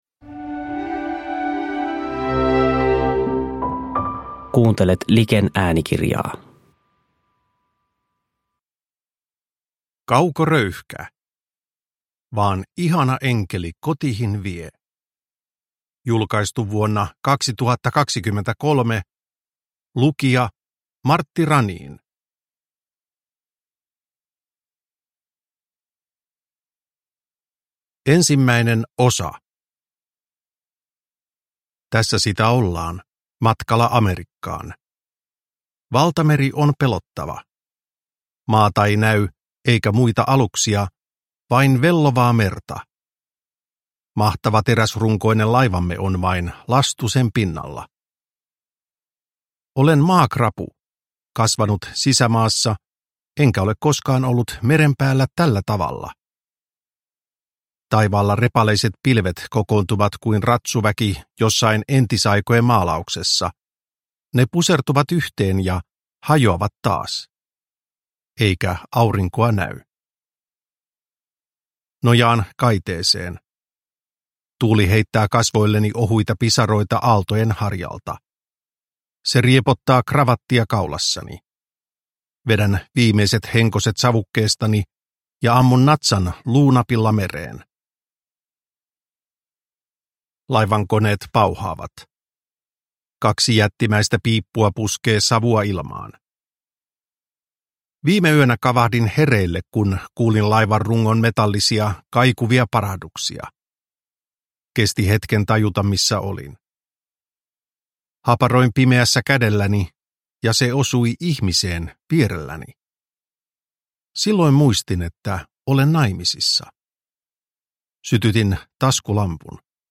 Vaan ihana enkeli kotihin vie – Ljudbok – Laddas ner